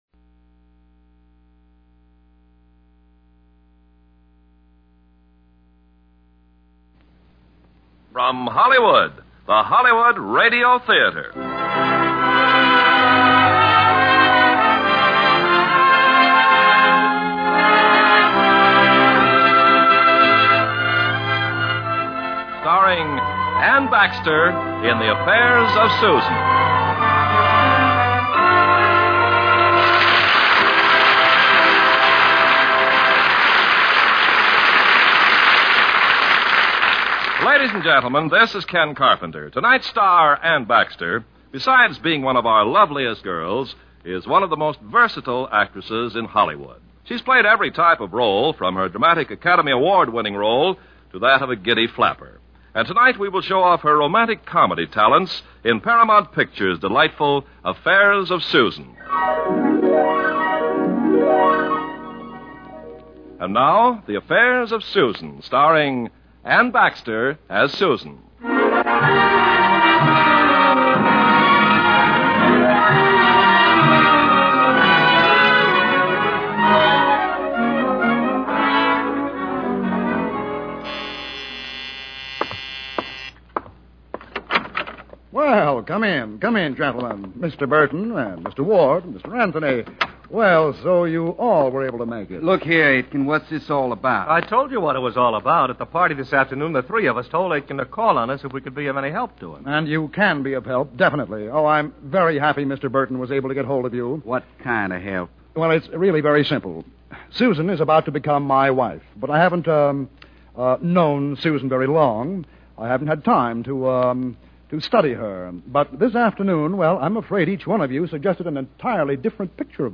Lux Radio Theater Radio Show